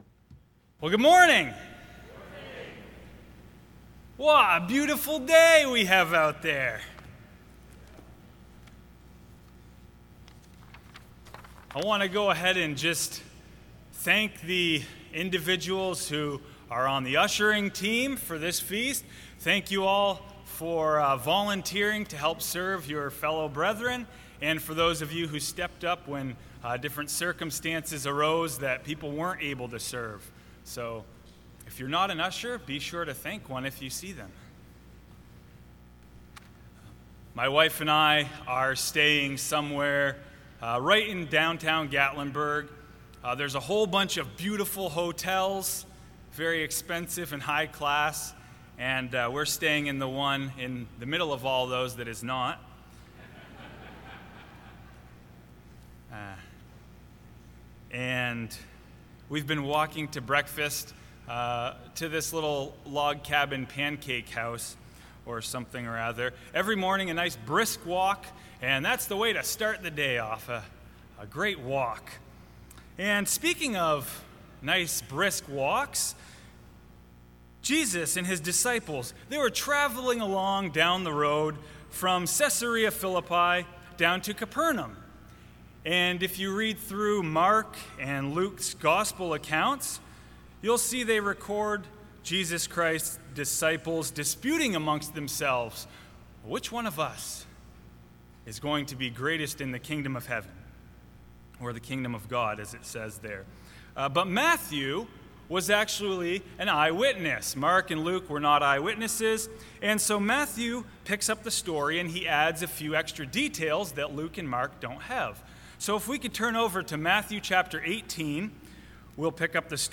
This sermon was given at the Gatlinburg, Tennessee 2020 Feast site.